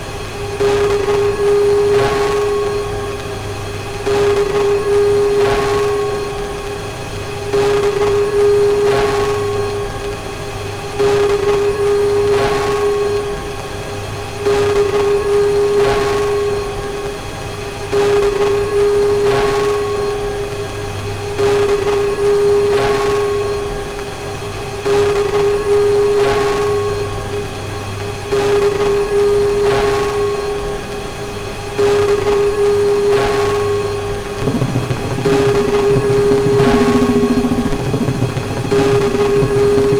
Listen to the eerie sounds of space debris soaring above your head
The Adrift project used an electromechanical instrument to transform the silent movement of 27,000 pieces of space junk into sound
The sounds engraved into the grooves were generated from recordings made by 250 individual pieces of so-called 'earthly debris'.